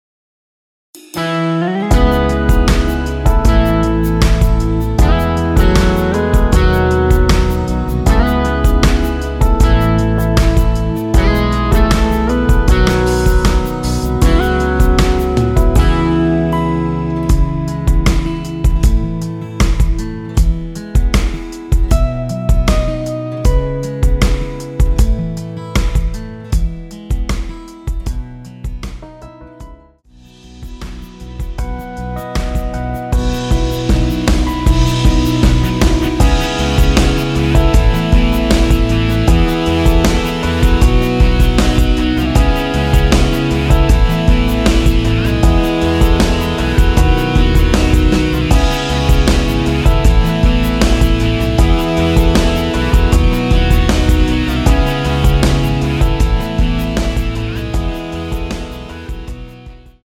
C#m
앞부분30초, 뒷부분30초씩 편집해서 올려 드리고 있습니다.
중간에 음이 끈어지고 다시 나오는 이유는